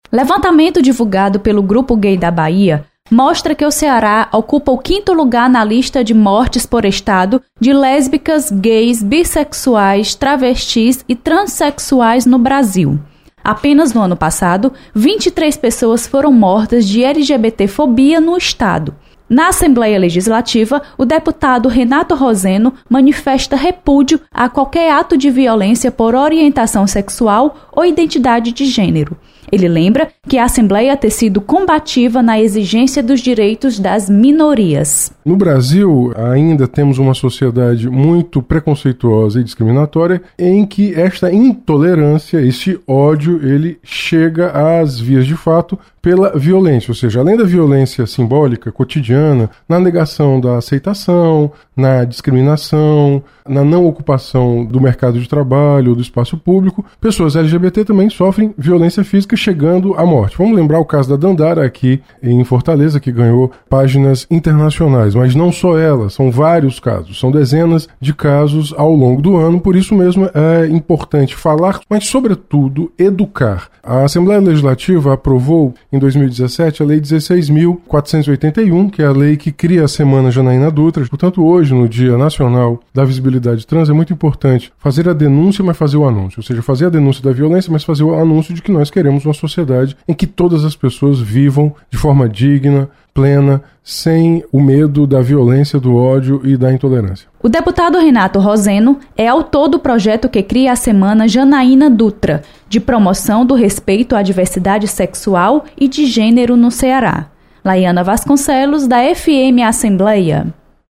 Deputado condena morte por preceito de gênero. Repórter